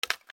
/ M｜他分類 / L01 ｜小道具 /
古く乾燥した木材 物音
『カタン』